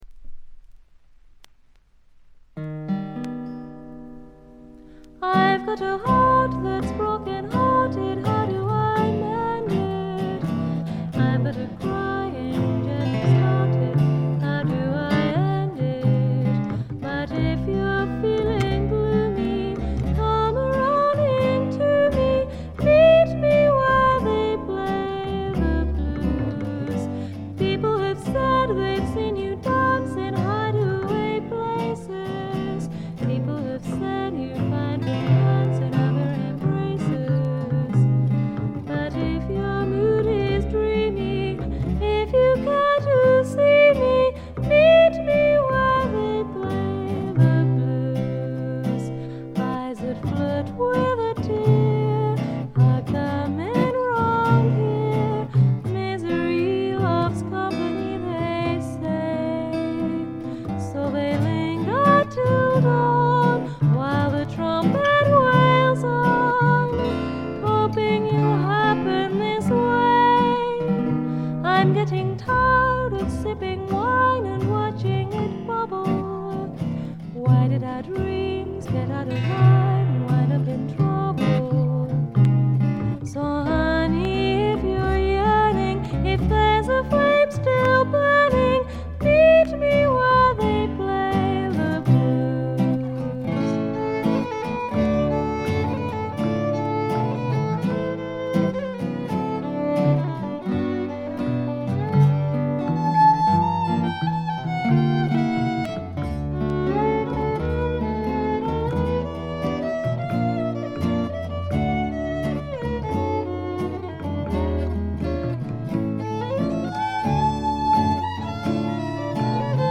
軽微なチリプチ少しだけ。
ほとんどこの二人だけで演奏しておりかなりの腕達者です。
オールドタイミーなグッタイム・ミュージック好きな方やフィメールものがお好きな方ならばっちりでしょう。
試聴曲は現品からの取り込み音源です。